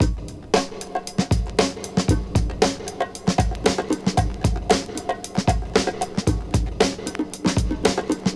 • 115 Bpm Fresh Drum Groove G# Key.wav
Free drum loop sample - kick tuned to the G# note. Loudest frequency: 1219Hz
115-bpm-fresh-drum-groove-g-sharp-key-BOm.wav